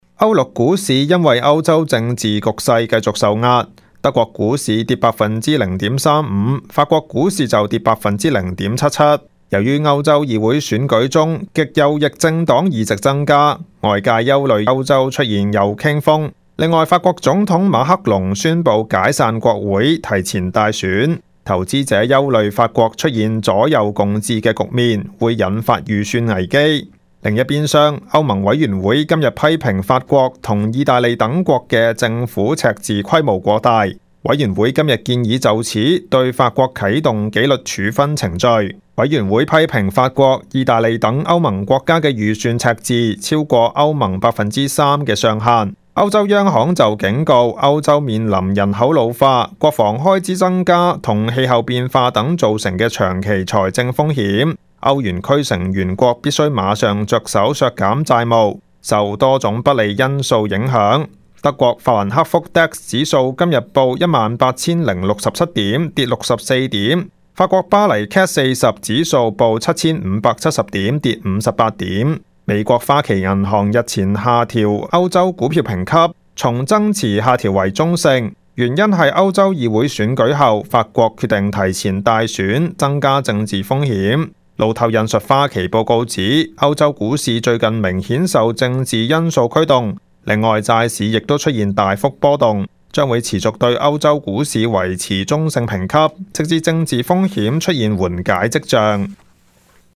Canada/World News 全國/世界新聞